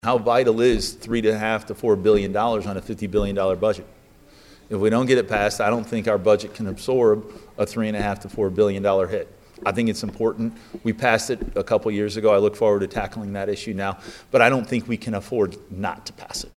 Plocher, asked during the Republicans’ media conference about the importance of FRA renewal, responded with his own question,